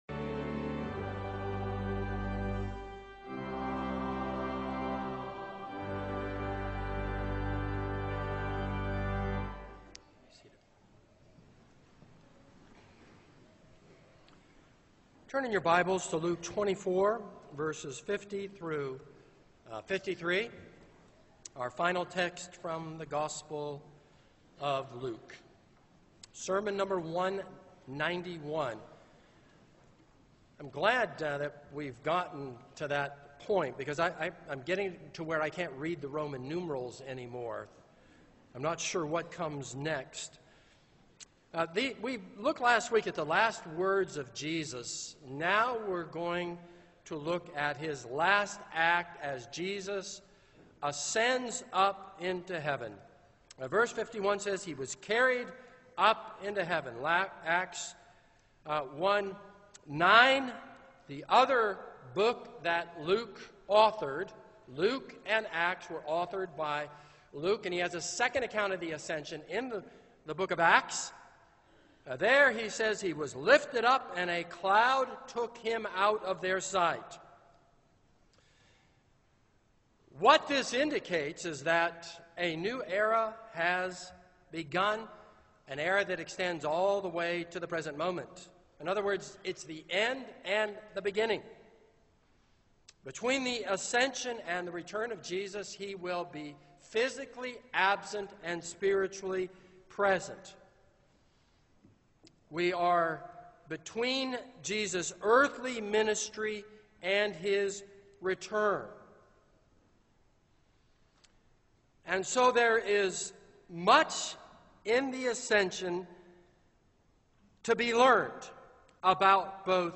This is a sermon on Luke 24:50-53.